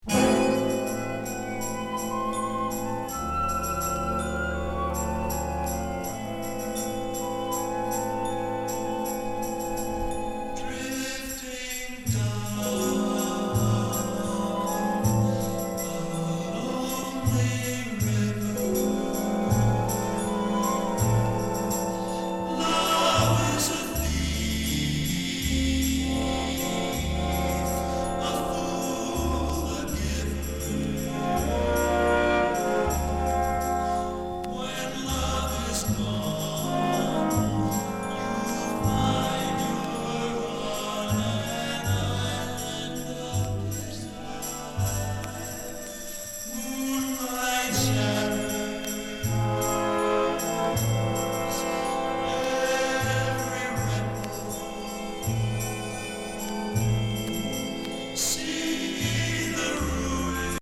カーレース音から仏教フィールドREC.なども収録されたモンド・ジャズ企画盤。